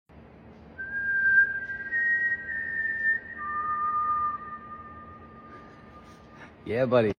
I'm a nut for large places which create a great echo sound!
I always whistle
It just sounds epic!